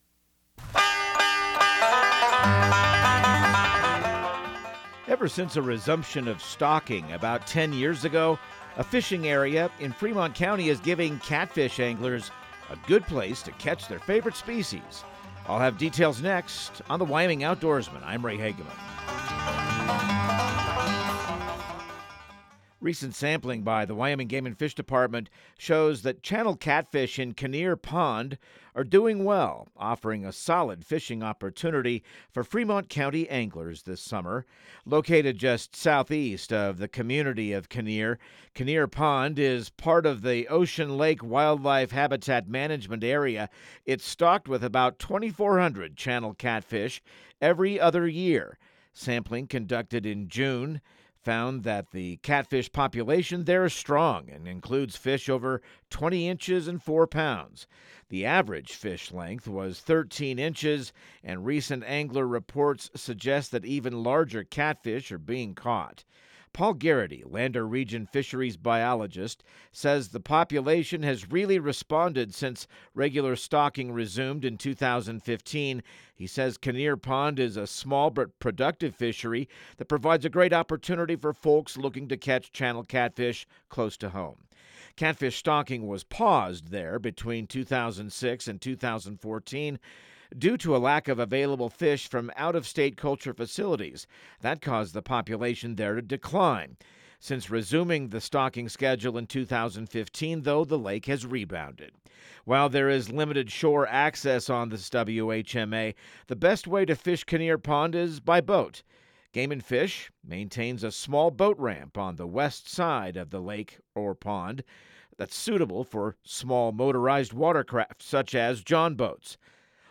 Radio news | Week of July 14